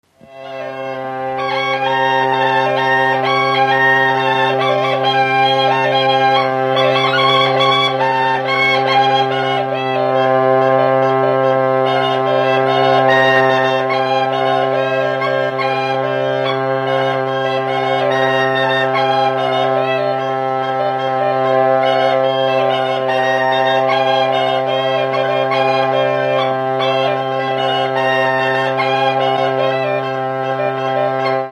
Dallampélda: Hangszeres felvétel
Hangszeres felvétel Felföld - Hont vm. - Palást Előadó
duda Gyűjtő